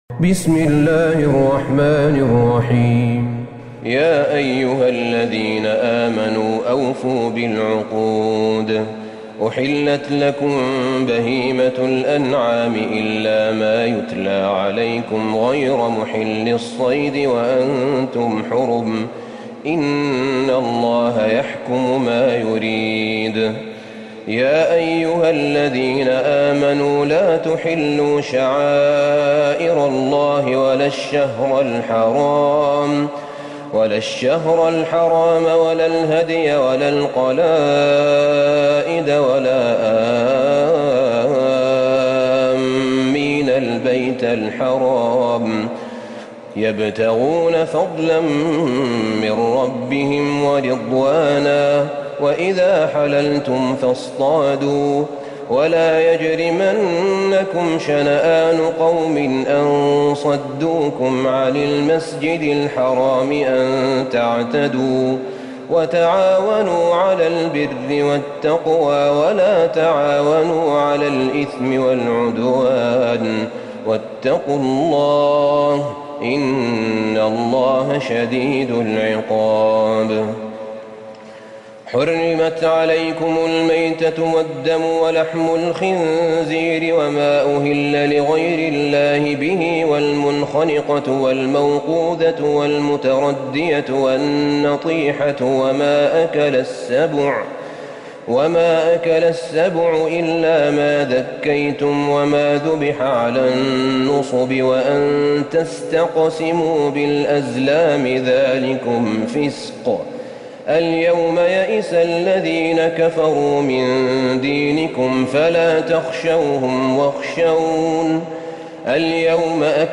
سورة المائدة Surat Al-Ma'idah > مصحف الشيخ أحمد بن طالب بن حميد من الحرم النبوي > المصحف - تلاوات الحرمين